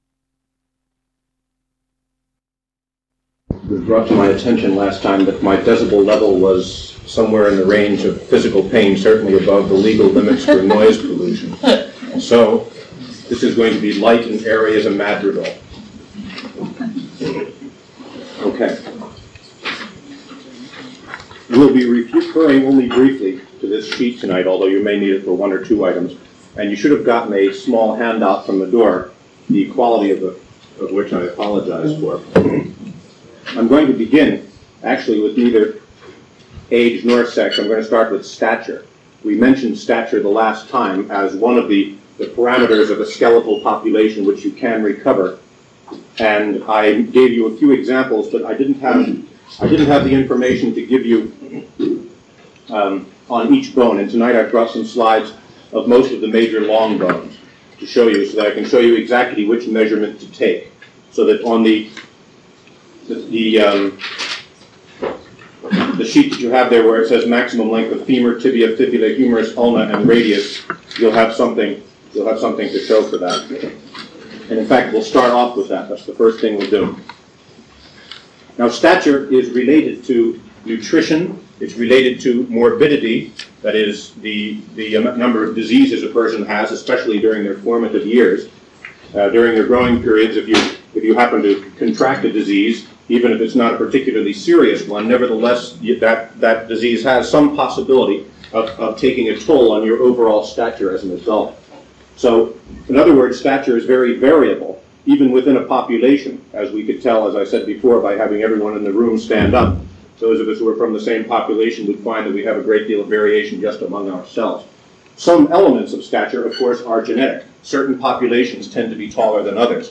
Archaeological Methodology - Lecture 15: Human Osteology - Age and Sex